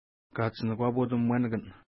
Pronunciation: ka:tʃinəkwa:pu:t umwenikən